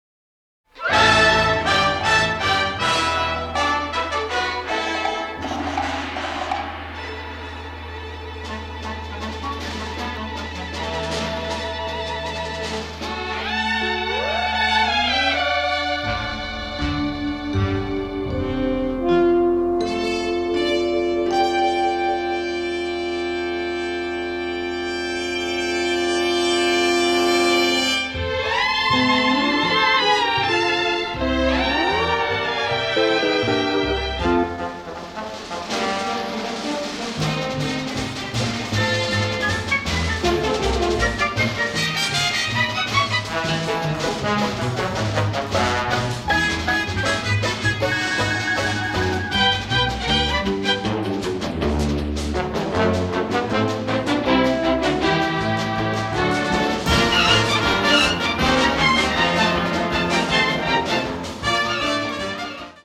rousingly patriotic score
recognizable war anthems mixed with shades of sarcasm